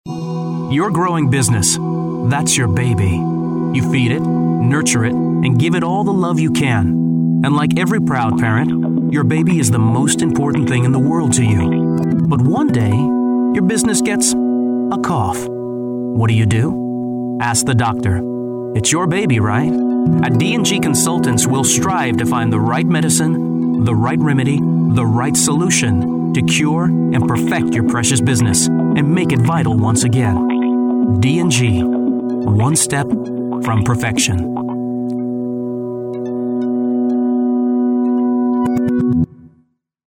SLightly poetic interpretation. Emotional. NArrative. Perfect Diction. Neutral American Accent.
Sprechprobe: Industrie (Muttersprache):